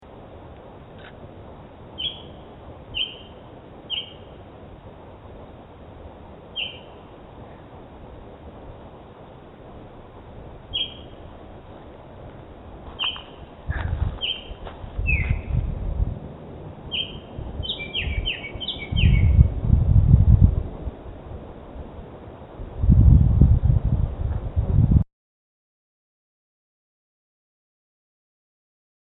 Near the creek, we heard a birdsong we’d not heard before. I looked up and saw a bright orange bird singing high up in the trees.
I also got a recording of his song.
There are several seconds of its short little call and then a bit of a song towards the end.
I call it an oriole song because although we’ve never seen one and the call doesn’t sound quite like the calls online, a Baltimore Oriole (Icterus galbula) is all we can figure it is around here.